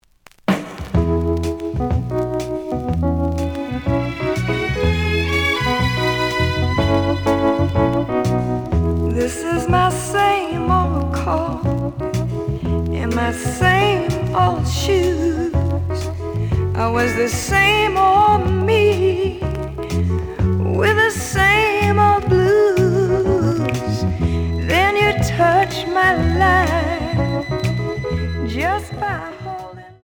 The audio sample is recorded from the actual item.
●Genre: Soul, 60's Soul
Slight noise on B side.)